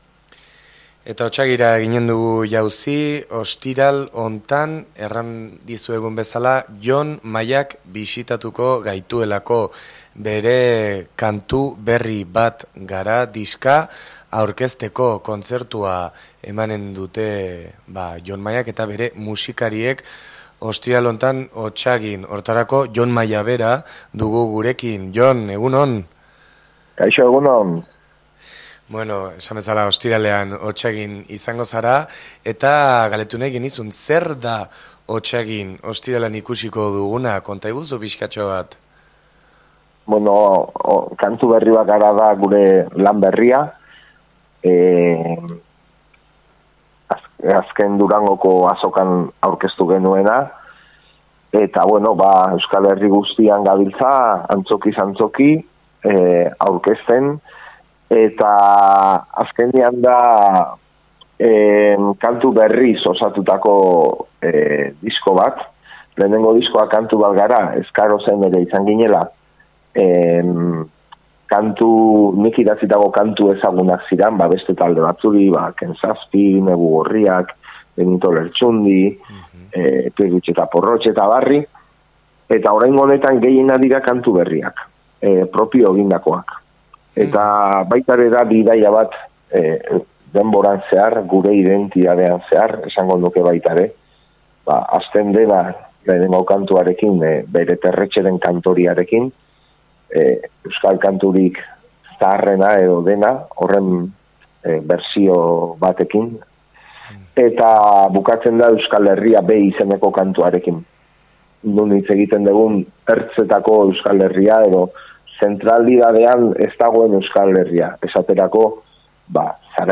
Gurekin solasten egon da Jon gaur goizean irratian.